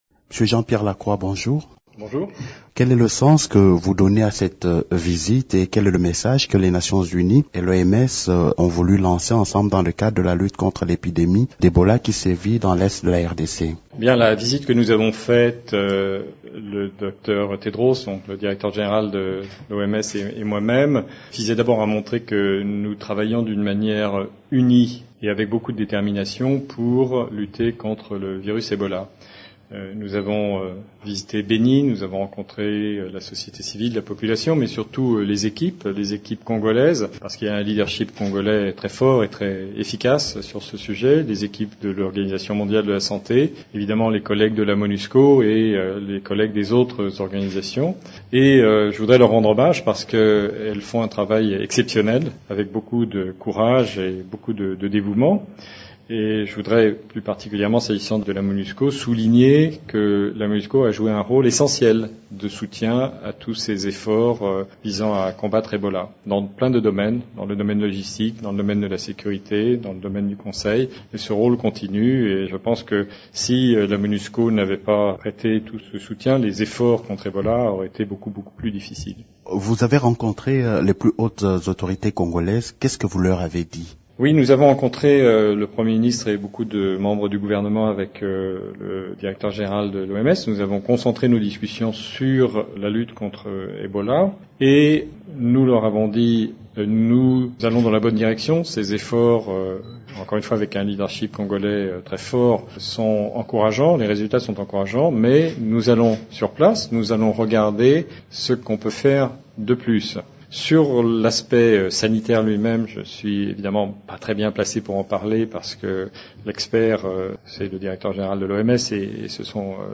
Retrouvez dans cet extrait, l’intégralité de l’interview de Jean-Pierre Lacroix. Il aborde les aspects liés à la lutte contre Ebola, les questions sécuritaires et politiques.